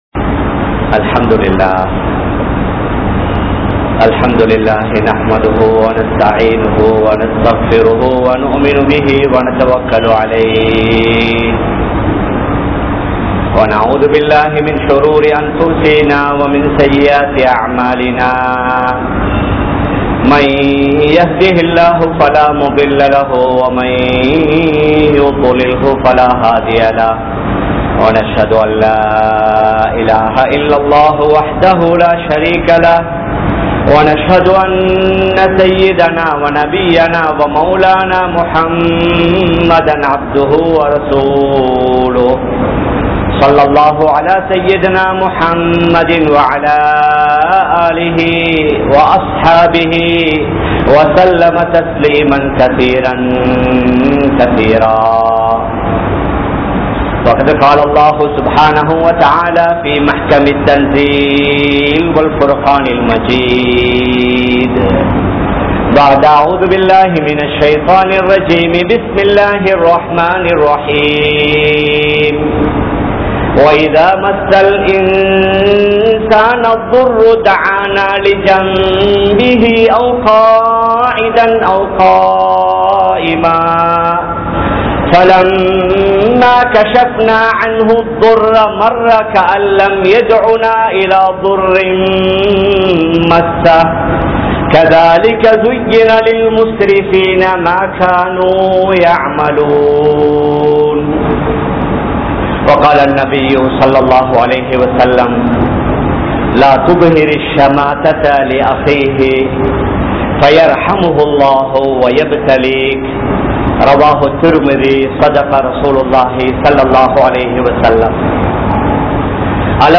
Moasamaaha Nadakkum Indraya Sila Muslimkal (மோசமாக நடக்கும் இன்றைய சில முஸ்லிம்கள்) | Audio Bayans | All Ceylon Muslim Youth Community | Addalaichenai
Kollupitty Jumua Masjith